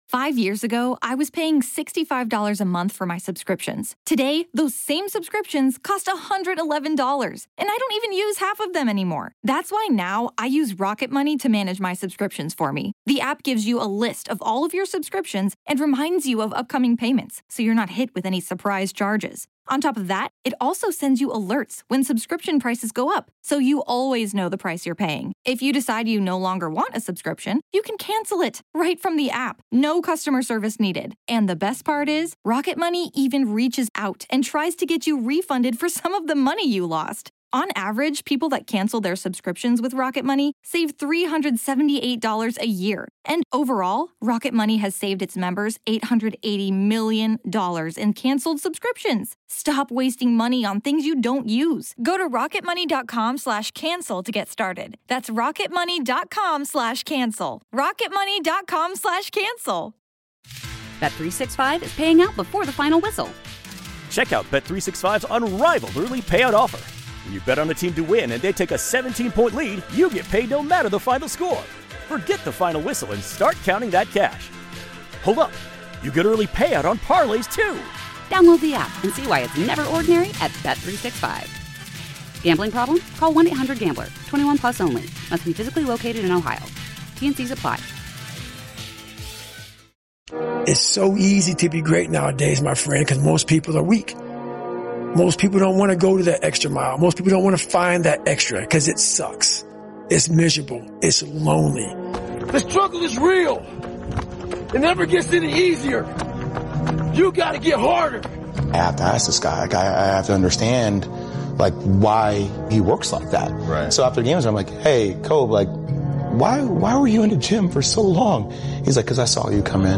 Are you ready to outwork everyone and achieve greatness? This motivational video compilation will fuel your passion and drive you towards your goals. Featuring powerful speeches from Eric Thomas and other motivational legends,